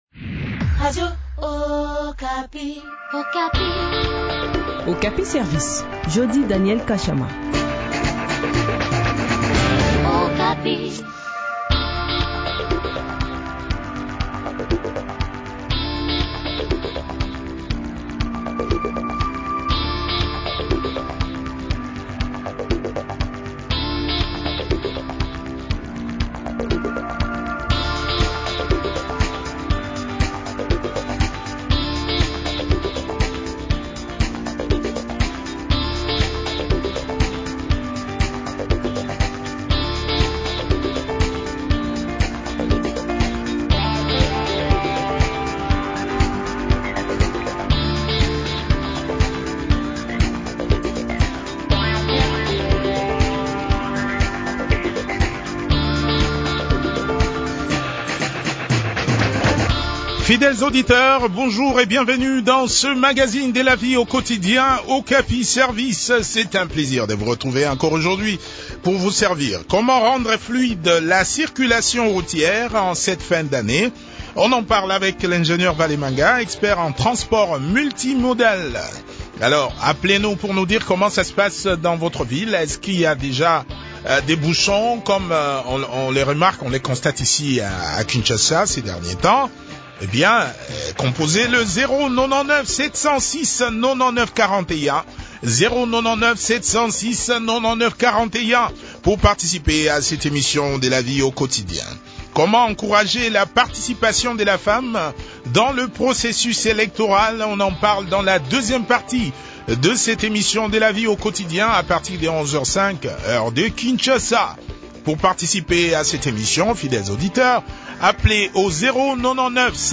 expert en transport multimodal.